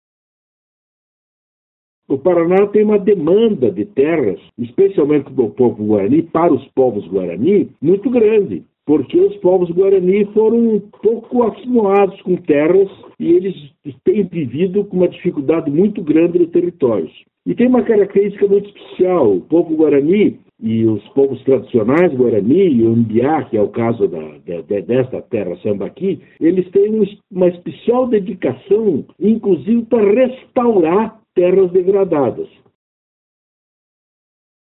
SONORA-DEMARCA-PARANA-02-BO.mp3